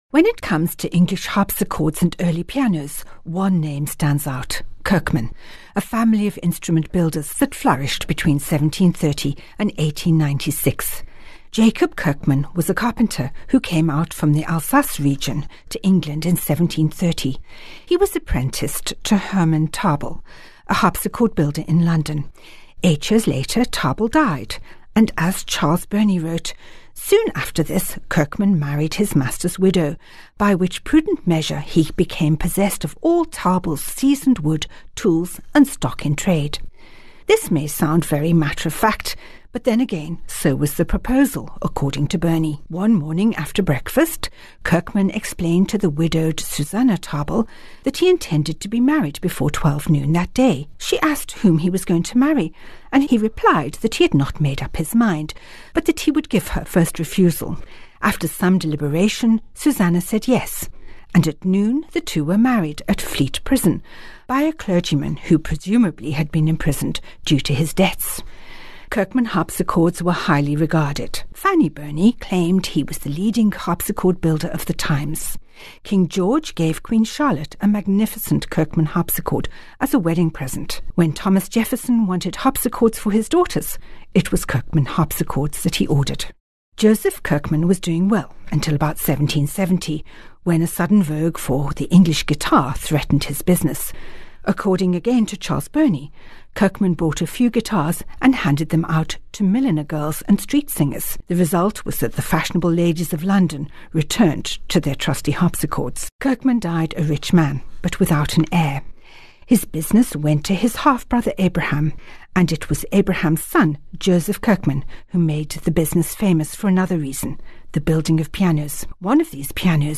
Each weekly Bon Bon is accompanied by a piece of Baroque music which ties in with the story.